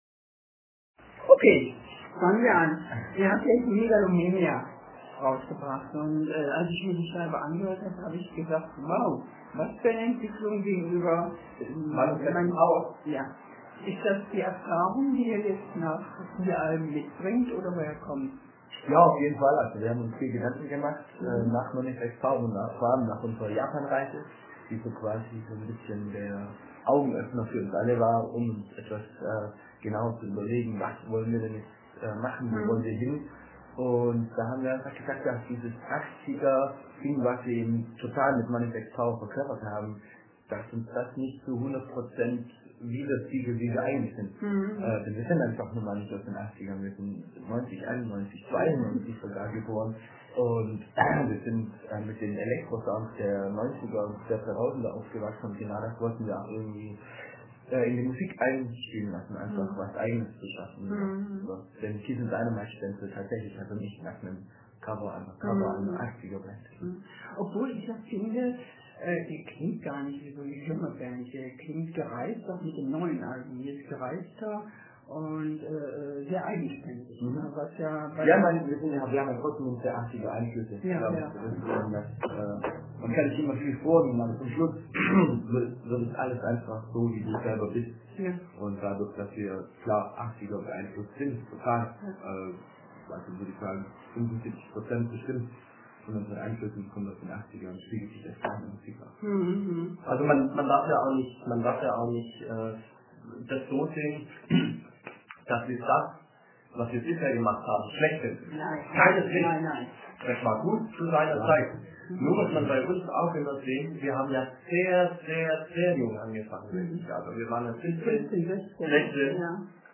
KISSIN� DYNAMITE: Es geht ganz klar nach oben! (Audiointerview | Nightshade Magazin)